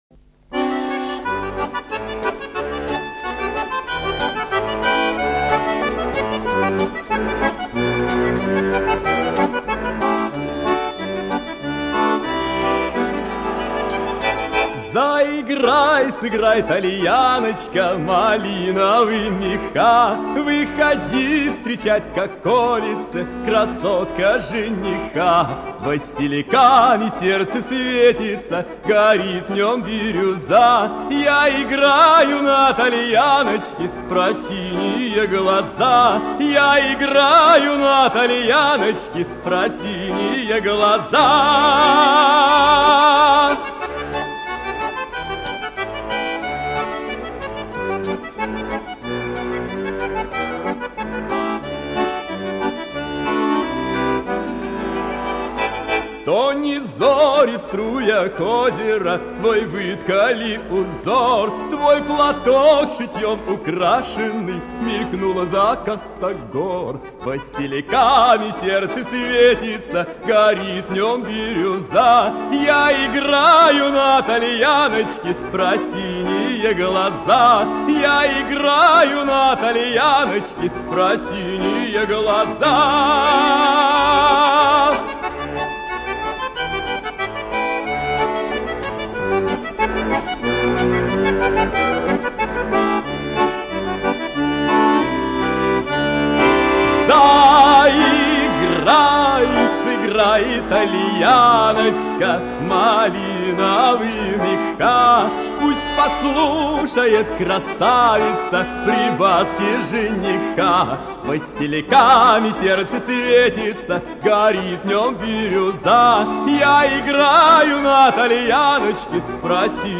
Прослушивание аудиозаписи песни